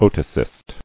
[o·to·cyst]